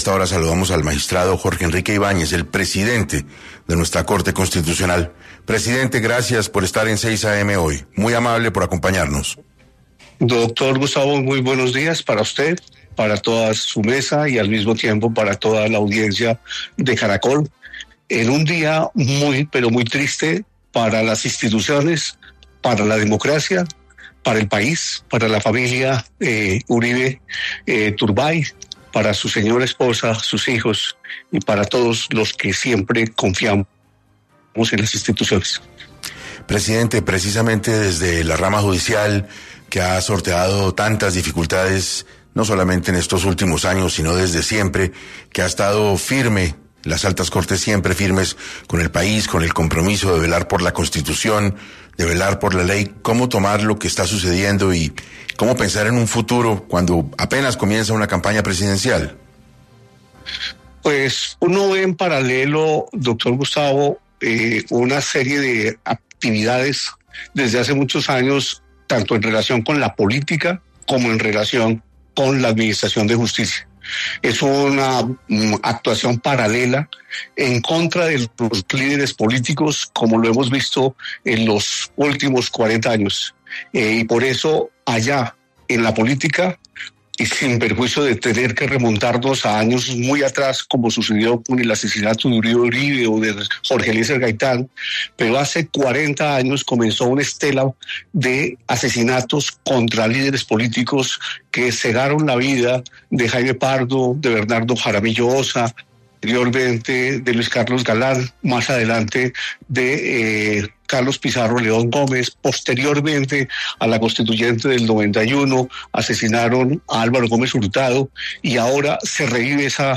Entrevista Octavio Augusto Tejeiro